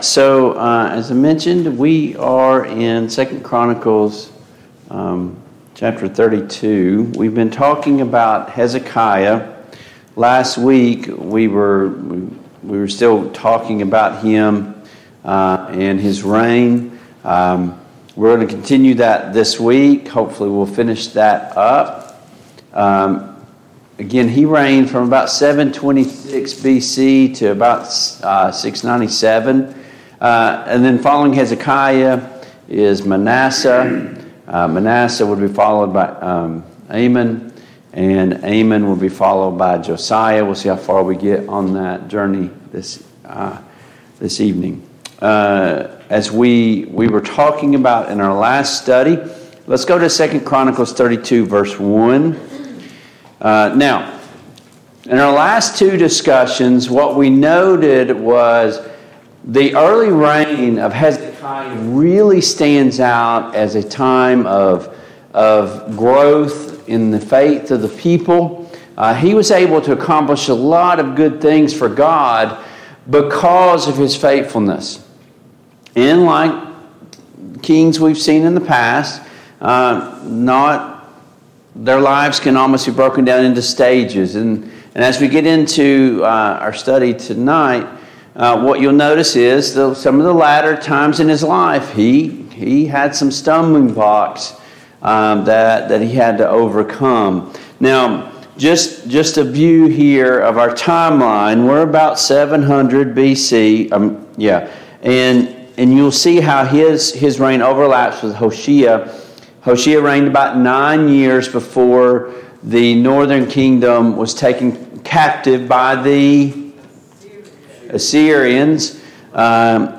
The Kings of Israel and Judah Passage: 2 Chronicles 32, 2 Kings 18 Service Type: Mid-Week Bible Study Download Files Notes « 9.